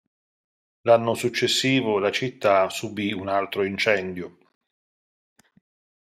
in‧cèn‧dio
/inˈt͡ʃɛn.djo/